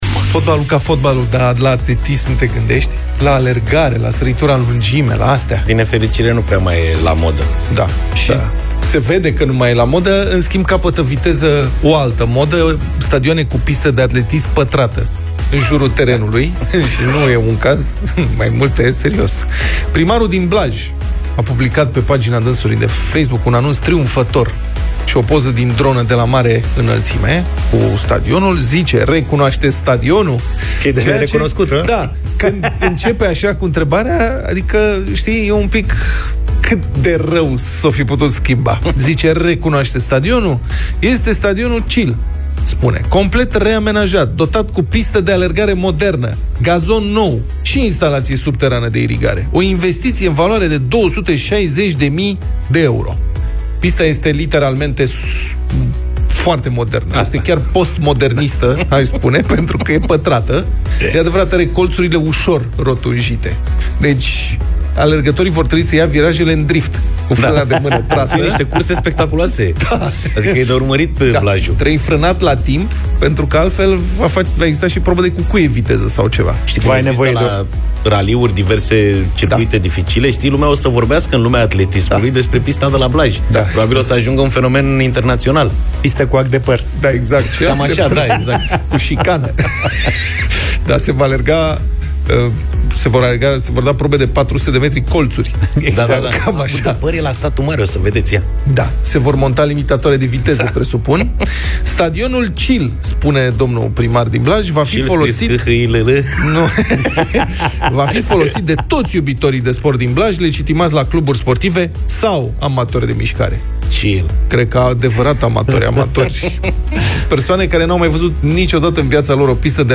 Puteți reasculta Deșteptarea din secțiunea podcast Europa FM, cu ajutorul aplicației gratuite pentru Android și IOS Europa FM sau direct în Spotify și iTunes.